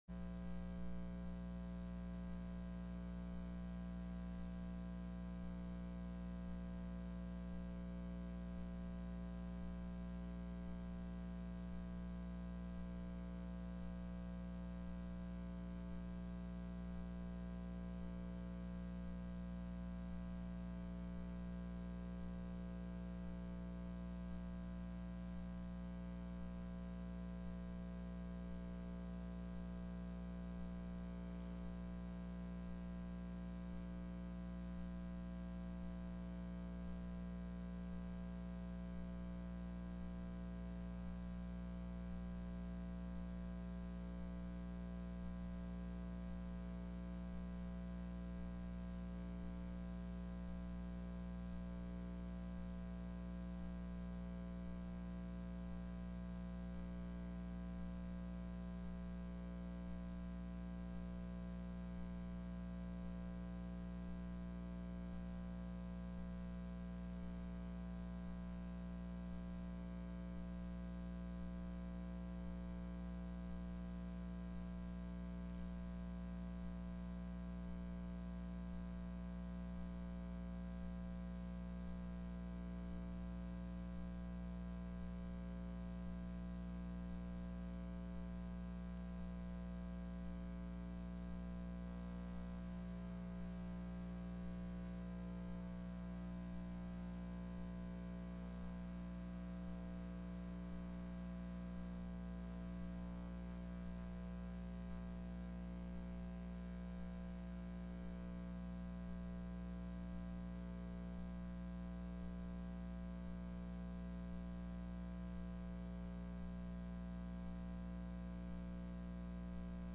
Oil and Gas Production Tax TELECONFERENCED